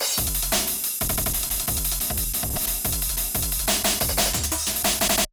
• 10 Rhythmic Loops: Infuse your music with groove and energy using versatile rhythmic loops that drive your tracks forward.
9-180-DnB-Rampage.wav